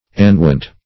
Search Result for " annuent" : The Collaborative International Dictionary of English v.0.48: Annuent \An"nu*ent\, a. [L. annuens, p. pr. of annuere; ad + nuere to nod.]